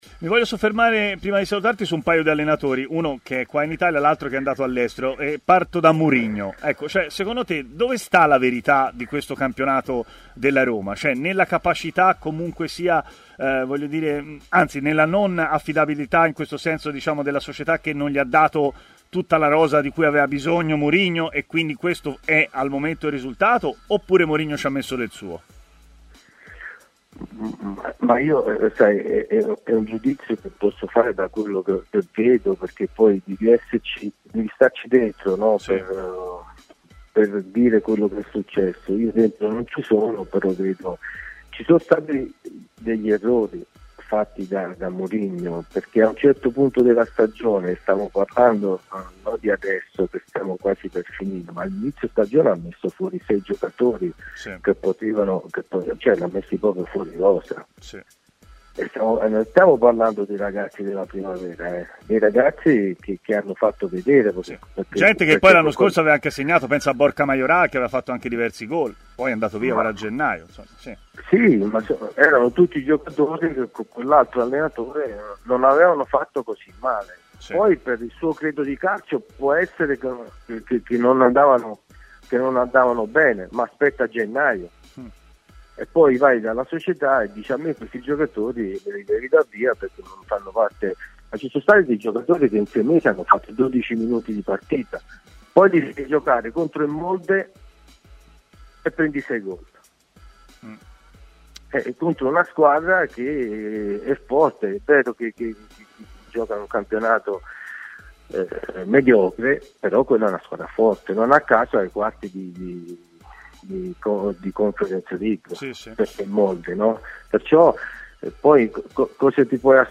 L'ex attaccante Francesco Baiano è così intervenuto su TMW Radio, durante la trasmissione Stadio Aperto, iniziando dalla partita di ieri di Coppa Italia: "La Fiorentina non meritava assolutamente di perdere, già il pareggio era molto stretto per quanto visto nei novanta minuti. Venuti è stato veramente sfortunato e la partita è stata persa".